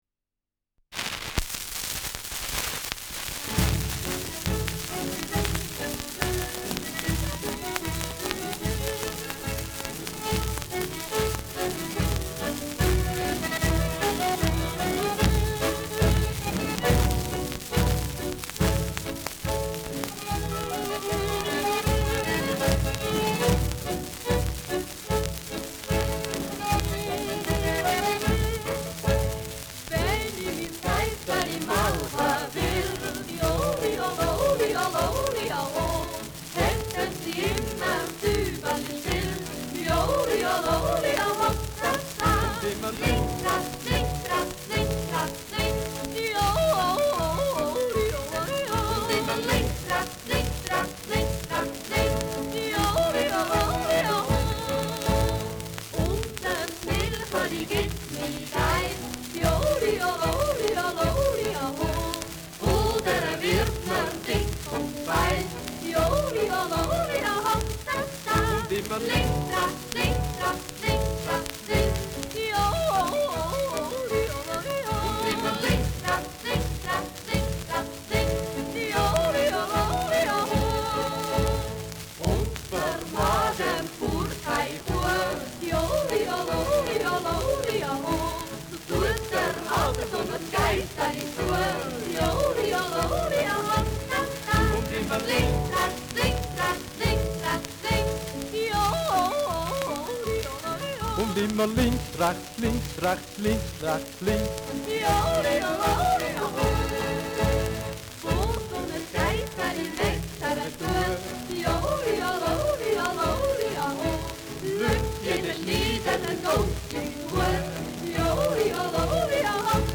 Schellackplatte
Tonrille: Riss 3 Uhr : Kratzer 8-12 Uhr Stärker
ausgeprägtes Rauschen
Jodlergruppe* FVS-00014